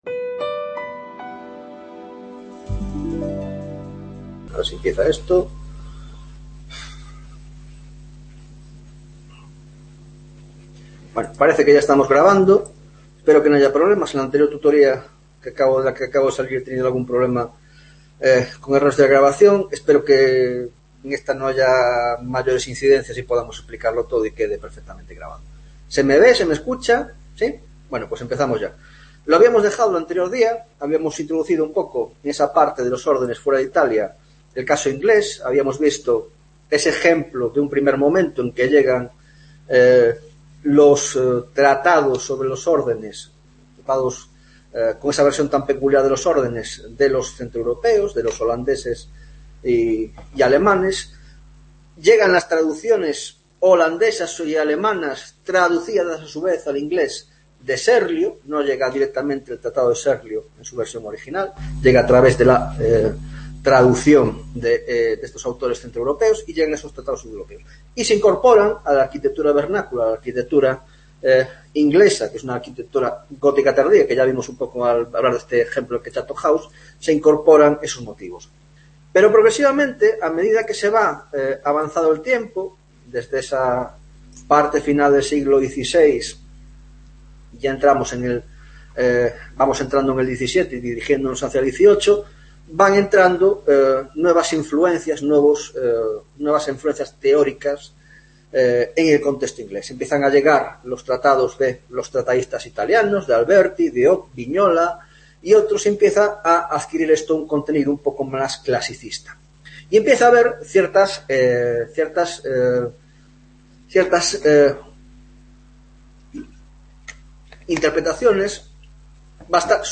7ª Tutoria de Órdenes y Espacio en la Arquitectura Moderna - Teoria de los Órdenes: Teoria de los Órdenes fuera de Italia (Inglaterra y España)